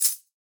soft-hitwhistle.ogg